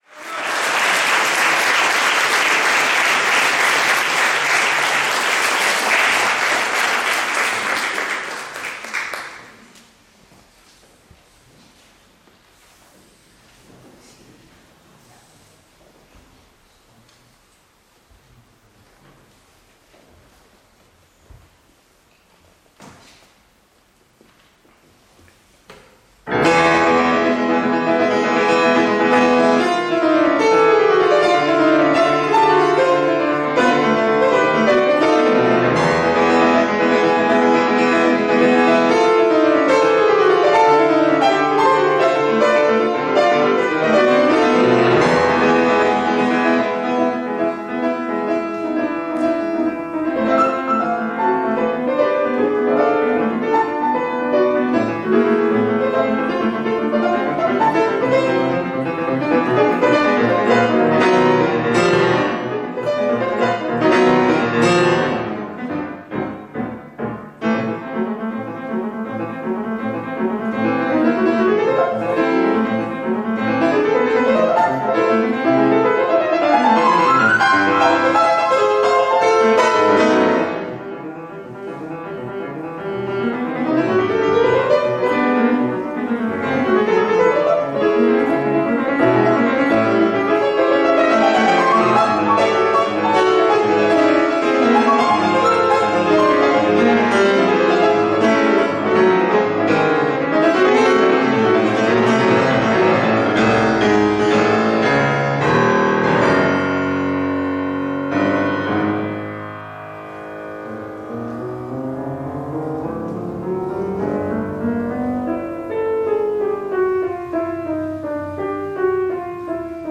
recorded live in 2012, Bologna